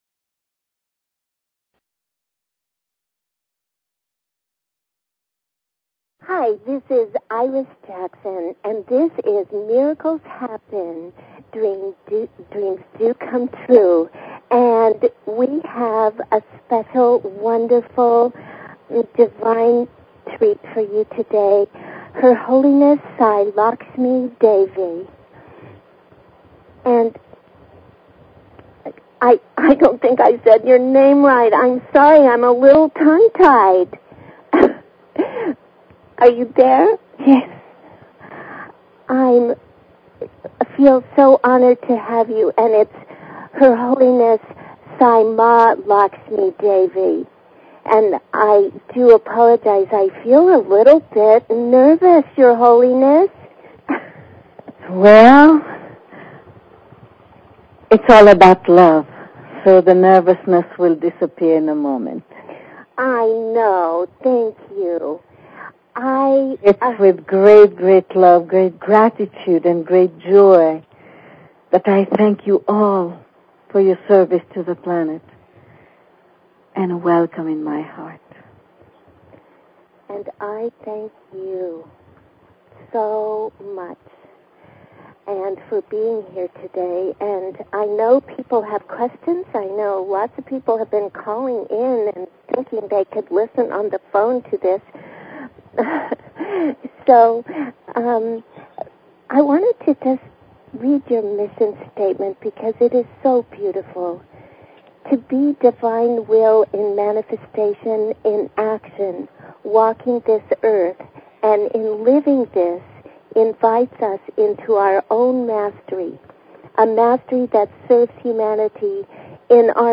Talk Show Episode, Audio Podcast, Miracles_Happen and Courtesy of BBS Radio on , show guests , about , categorized as